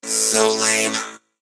mech_edgar_die_vo_06.ogg